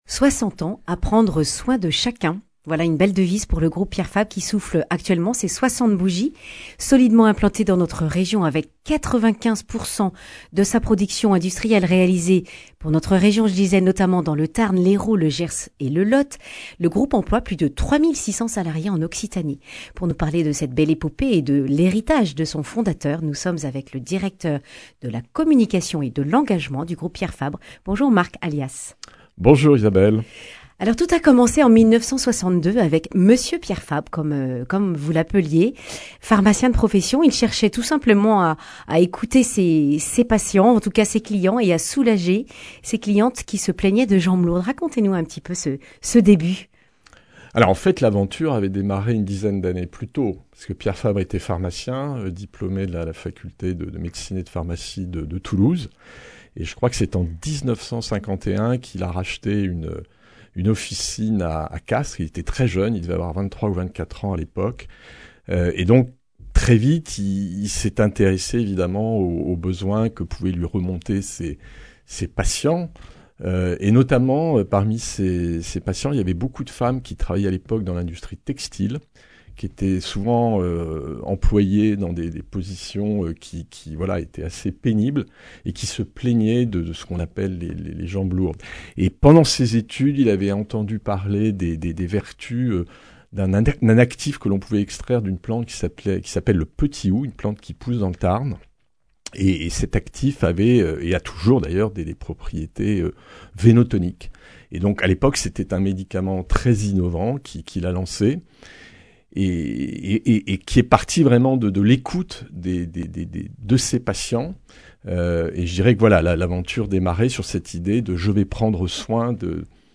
Accueil \ Emissions \ Information \ Régionale \ Le grand entretien \ Le groupe Pierre Fabre fête ses 60 ans !